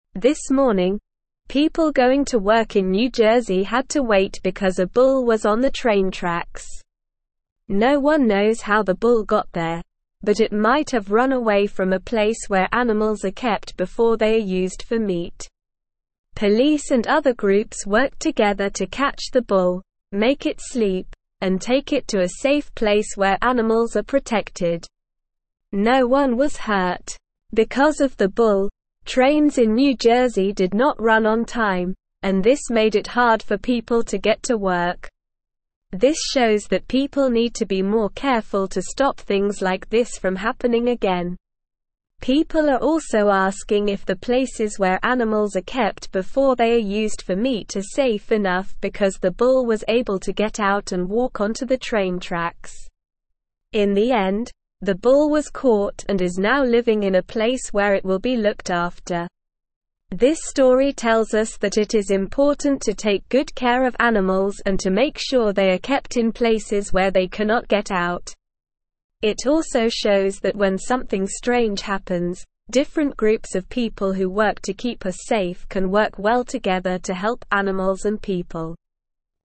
Slow
English-Newsroom-Lower-Intermediate-SLOW-Reading-Bull-on-Train-Tracks-Causes-Trouble-Gets-Help.mp3